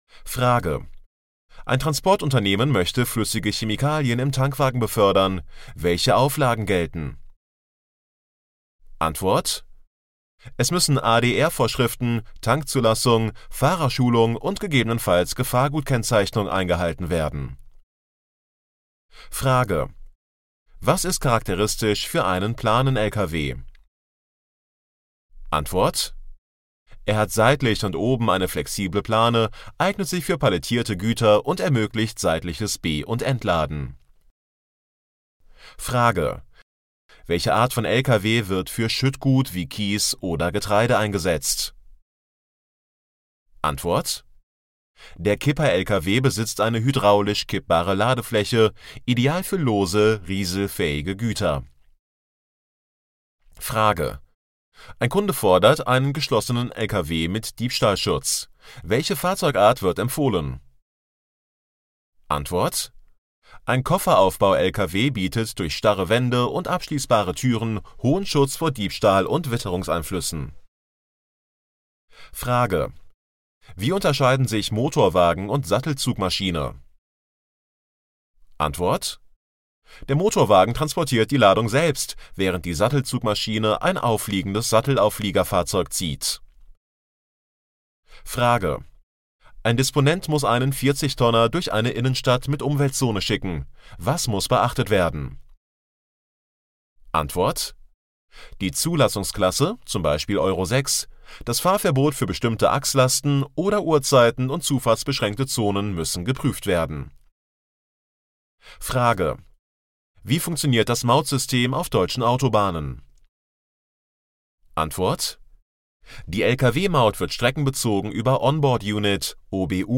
MP3 Hörbuch Kaufmann für Spedition und Logistikdienstleistung Verkehrsträger - Download
Hörprobe Kaufmann für Spedition und Logistikdienstleistung Verkehrsträger